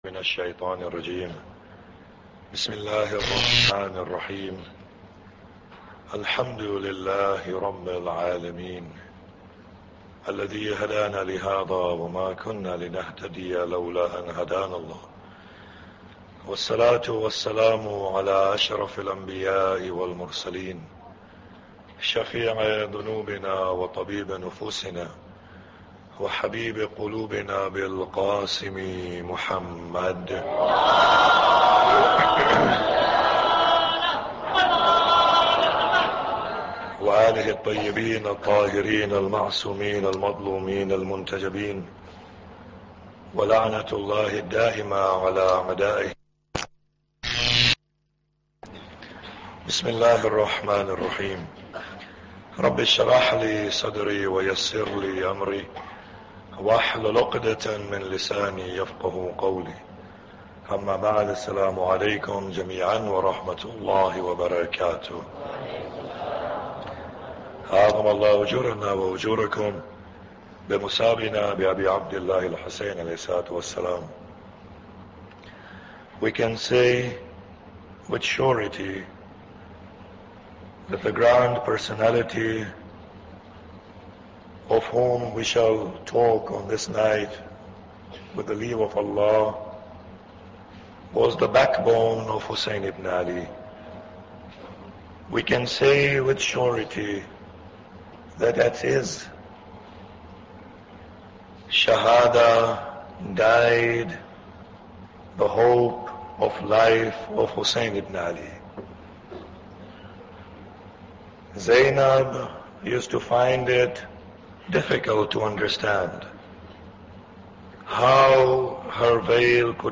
Muharram Lecture 8